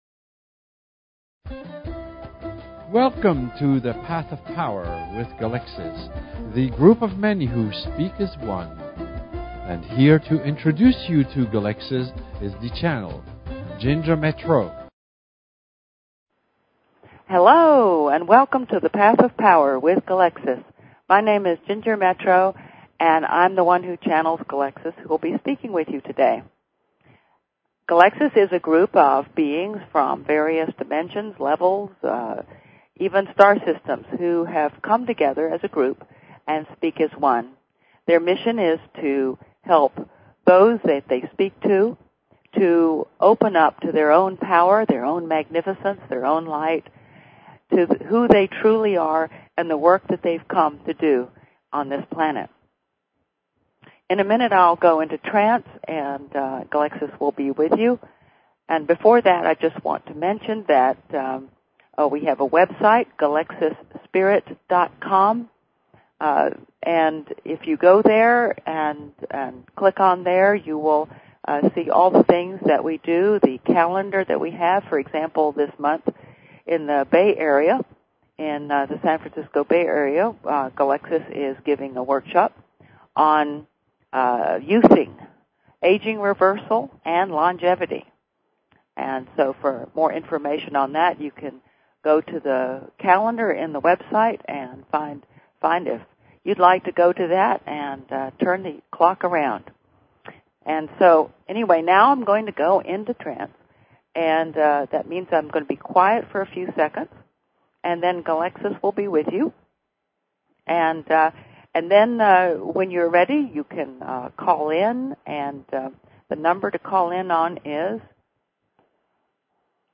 Talk Show Episode, Audio Podcast, Path_of_Power and Courtesy of BBS Radio on , show guests , about , categorized as